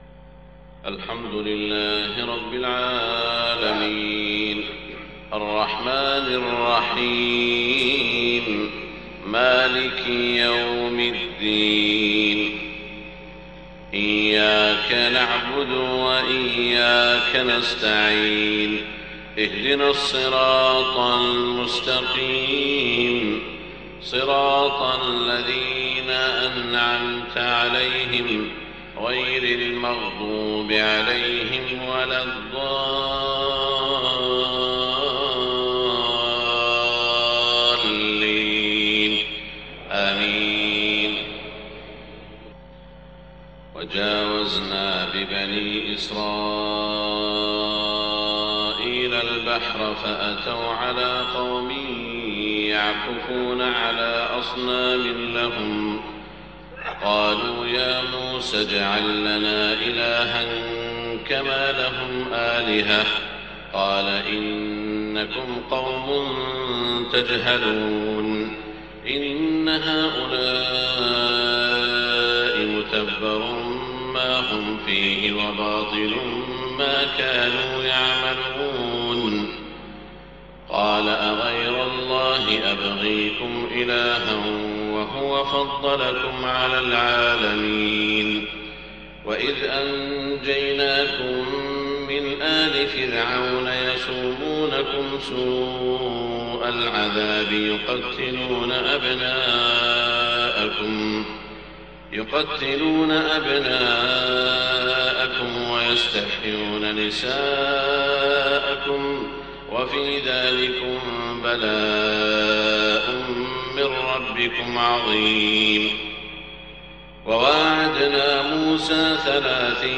صلاة الفجر 23 صفر 1430هـ من سورة الأعراف > 1430 🕋 > الفروض - تلاوات الحرمين